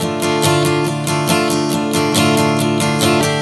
Strum 140 Dm 02.wav